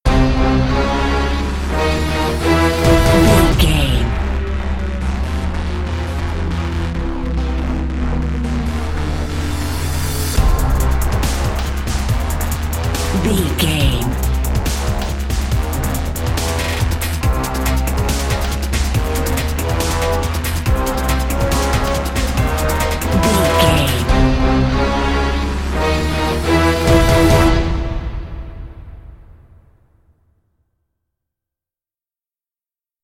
Epic / Action
Aeolian/Minor
strings
drum machine
synthesiser
brass
driving drum beat